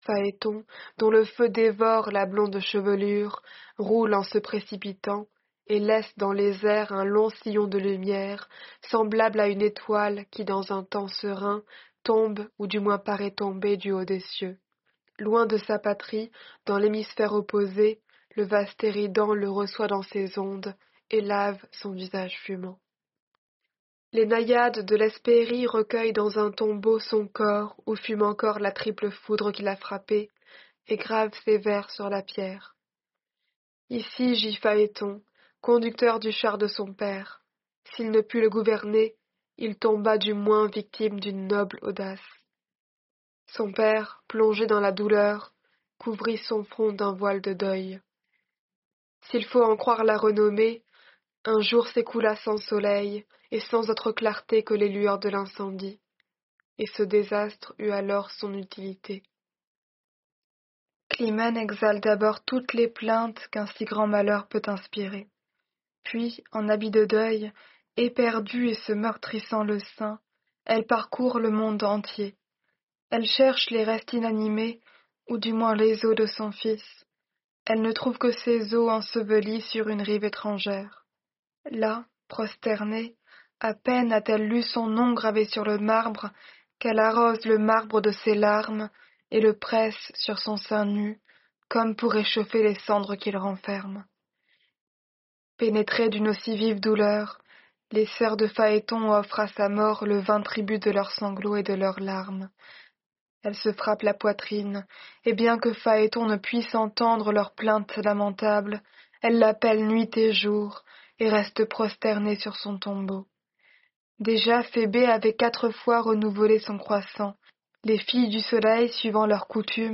Lecture de la métamorphose des Héliades · GPC Groupe 1